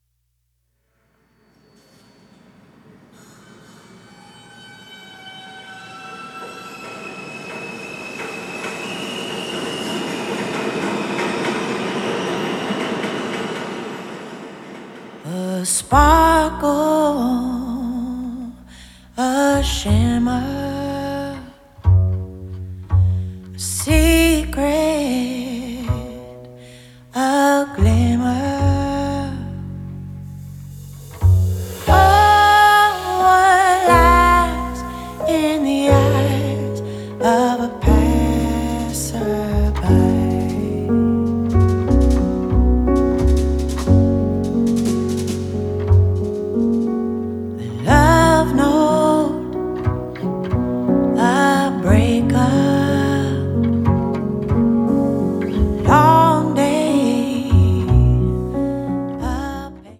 double bass
piano and keyboards
drums
Recorded at Sorriso Studio